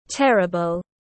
Rất khó chịu tiếng anh gọi là terrible, phiên âm tiếng anh đọc là /ˈterəbl/
Terrible /ˈterəbl/